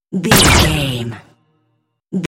Dramatic stab laser
Sound Effects
Atonal
heavy
intense
dark
aggressive
hits